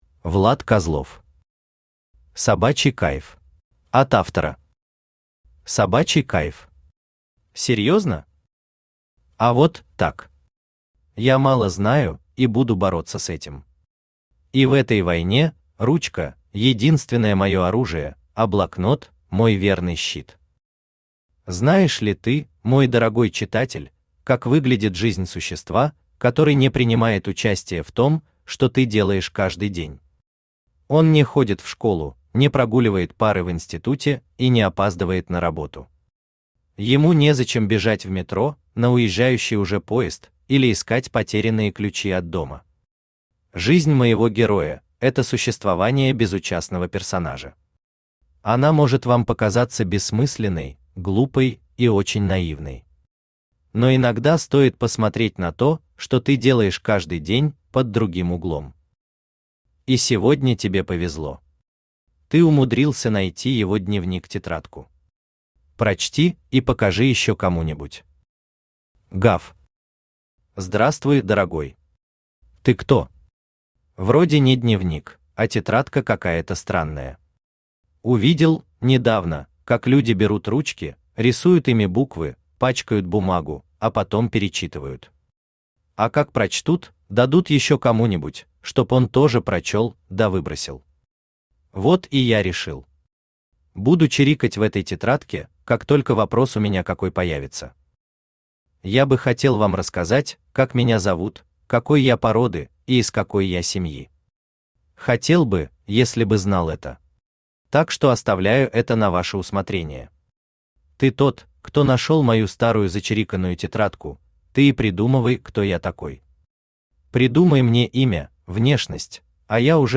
Аудиокнига Собачий кайф | Библиотека аудиокниг
Aудиокнига Собачий кайф Автор Влад Козлов Читает аудиокнигу Авточтец ЛитРес.